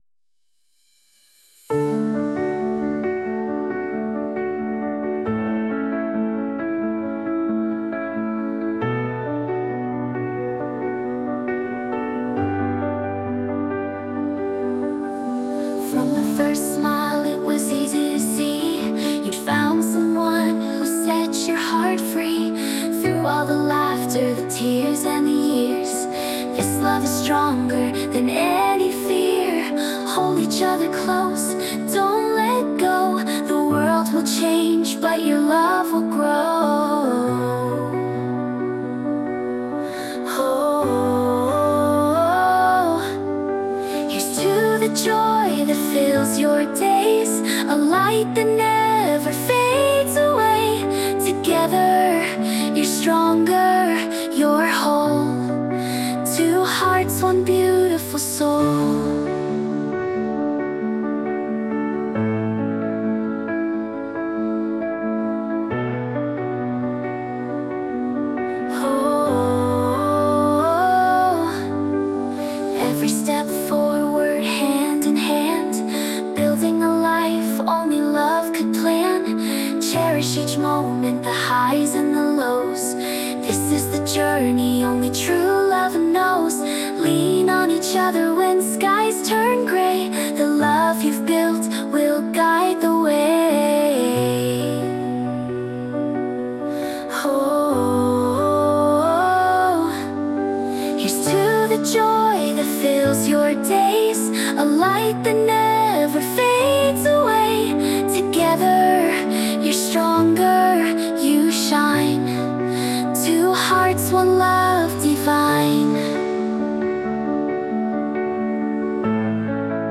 女性ボーカル洋楽 女性ボーカルプロフィールムービーエンドロール
著作権フリーオリジナルBGMです。
女性ボーカル（洋楽・英語）曲です。